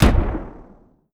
EXPLOSION_Arcade_15_mono.wav